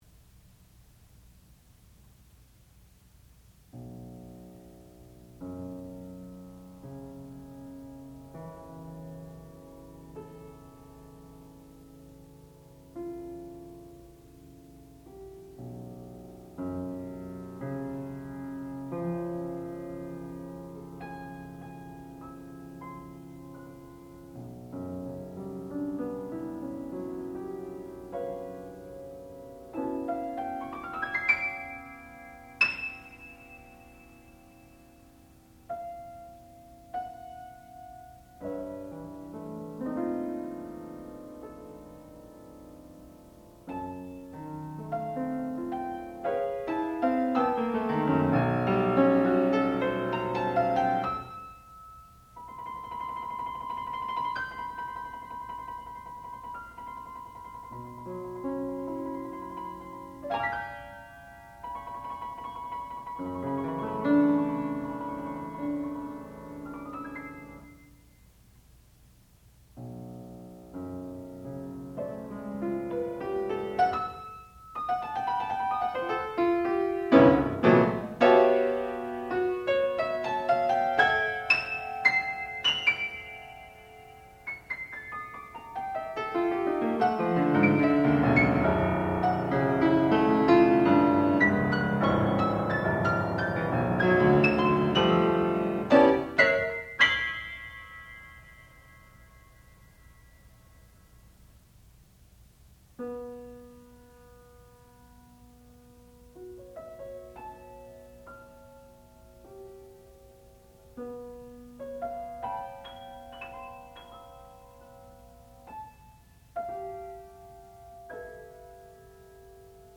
sound recording-musical
classical music
Junior Recital